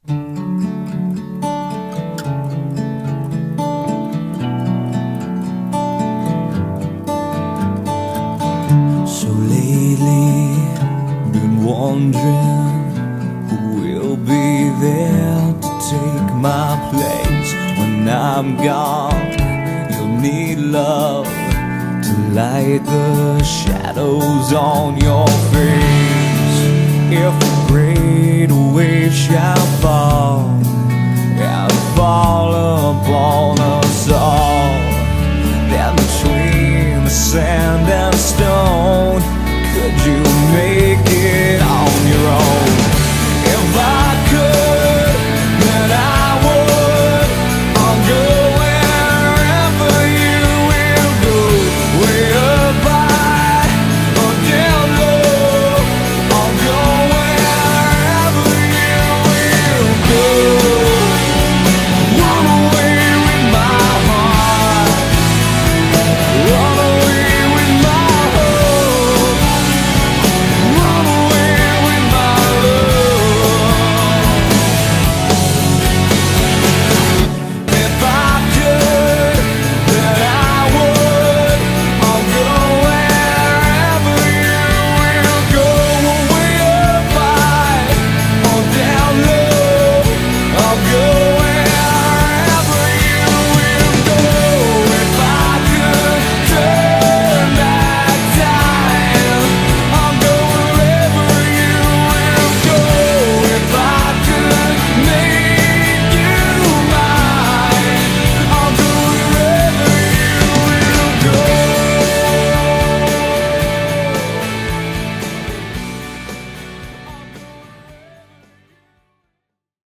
BPM110-115
Audio QualityMusic Cut